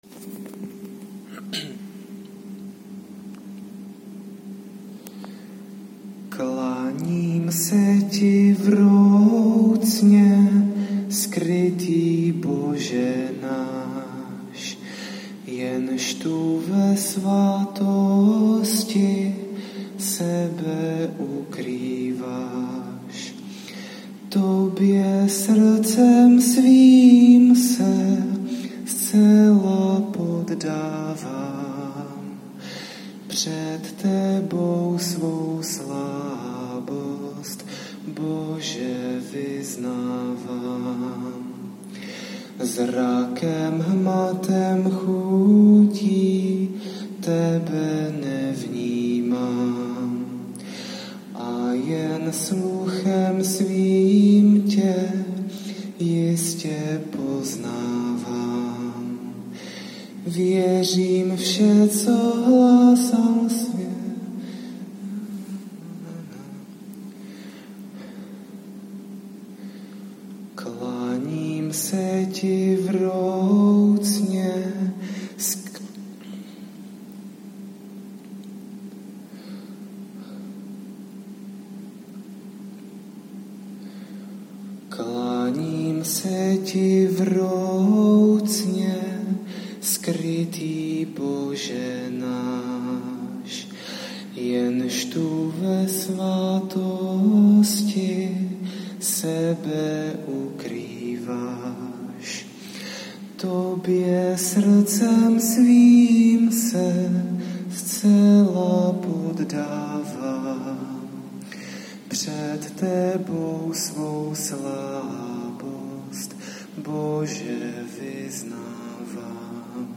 Co říkáte na můj začátečnický zpěv?
Za mně máte hlas příjemný, ale u této písně to chce hlasově důrazněji nebo se za zvuku varhan ve skladbě ztratíte.
V této ukázce jste moc jemný.
Mně se váš hlas líbí, je mladý a jakoby hladí.
Hraji na varhany a chtěl bych si k tomu zazpívat, nevím však, zda by to lidem spíše neutrhlo uši.
Príjemný, jemný hlas. Možno to chcelo dať bez echa.